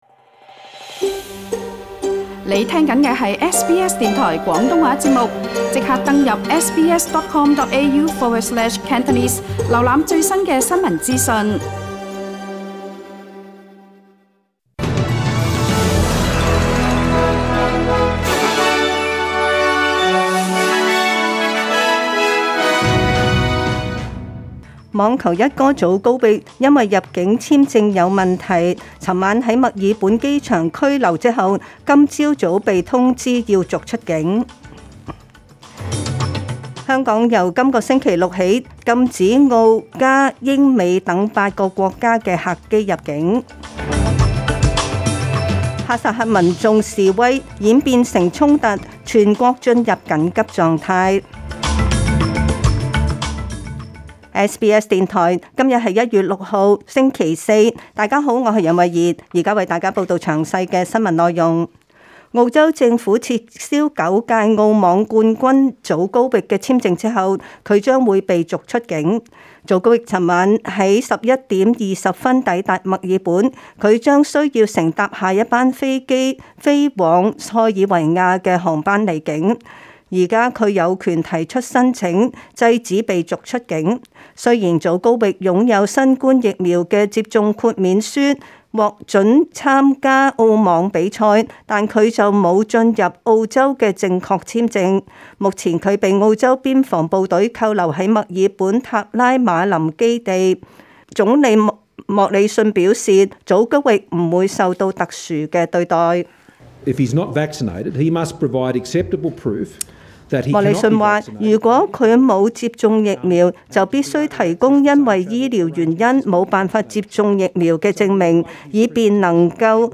SBS 中文新聞 （1月6日）